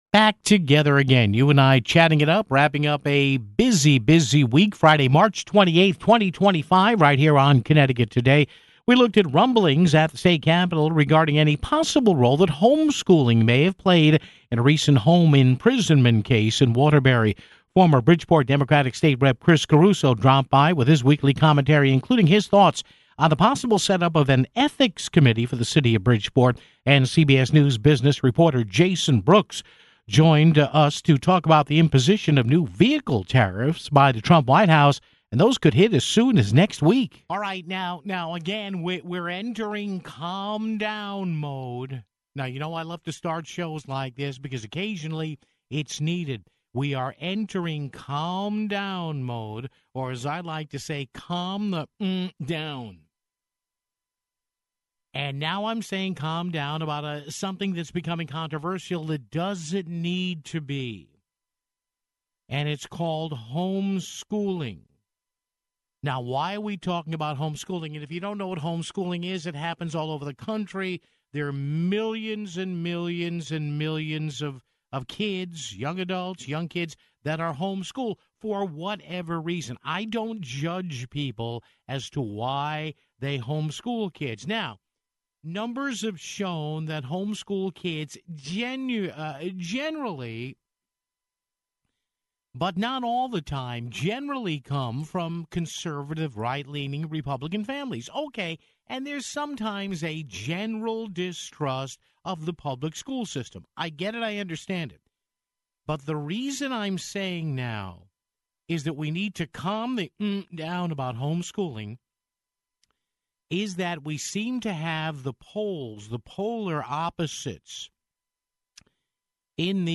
Former Bridgeport State Rep. Chris Caruso dropped by with his weekly commentary, including thoughts on possibly setting up a City Ethics Commission (16:38).